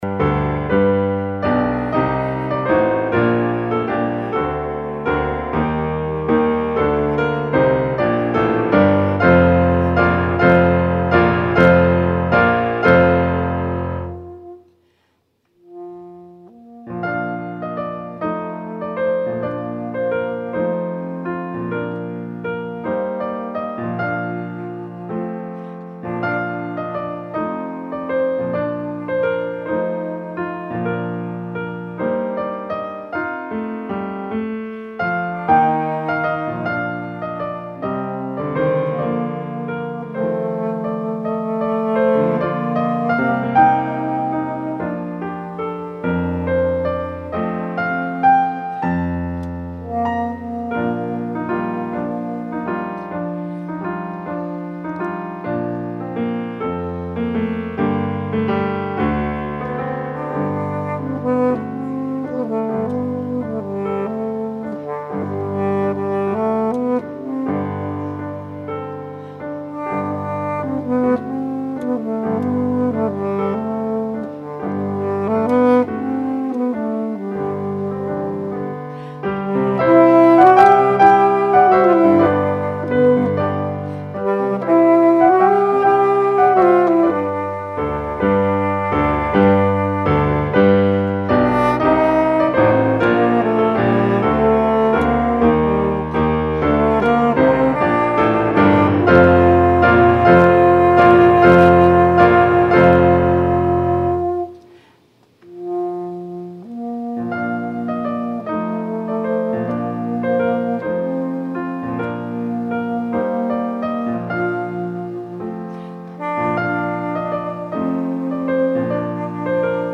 Christmas Eve Service